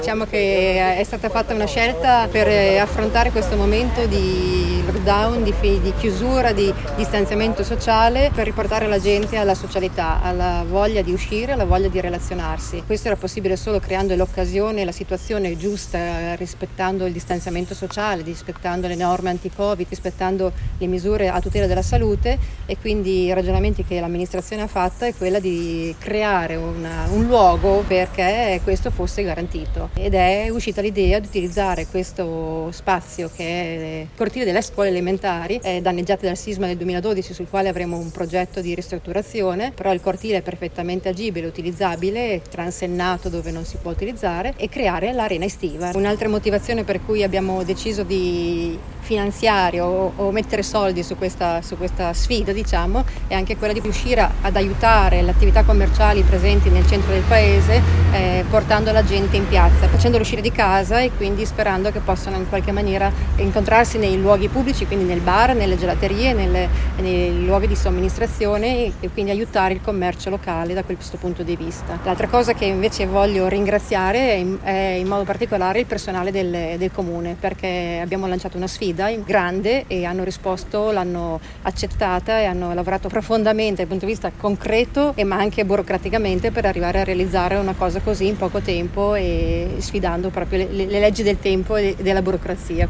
L’amministrazione comunale, guidata dalla sindaca Elisabetta Galeotti che la nostra redazione ha intervistato, ha creato un’Arena Estiva nel cortile delle ex scuole elementari, uno spazio ampio e perfettamente agibile per ospitare eventi e accogliere spettatori, nel rispetto delle misure anti contagio.
sindaca-gonzaga.mp3